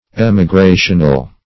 Emigrational \Em`i*gra"tion*al\, a. Relating to emigration.